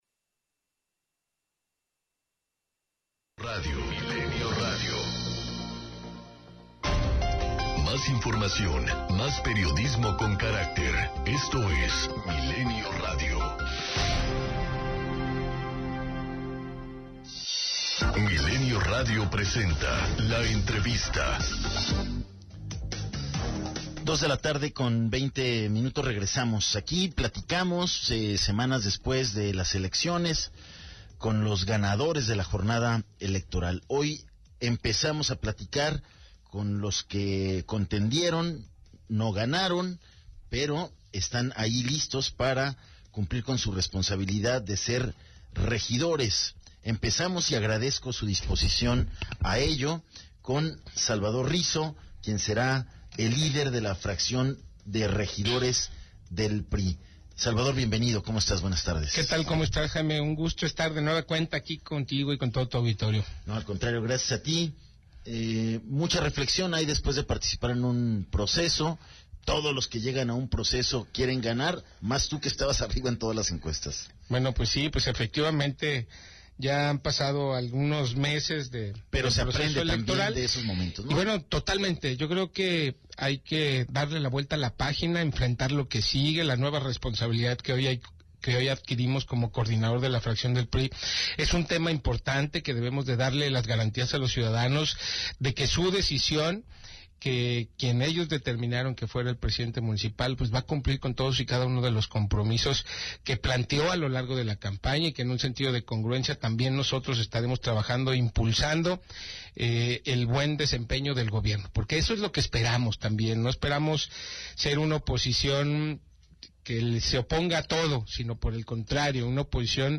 ENTREVISTA 210915